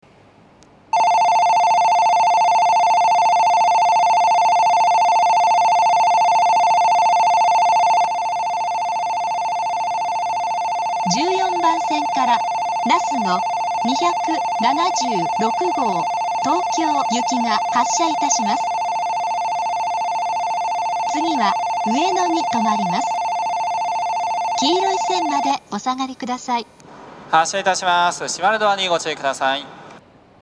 標準的な音程の発車ベルを使用していますが、１５・１６番線の発車ベルは音程が低いです。
１４番線発車ベル なすの２７６号東京行の放送です。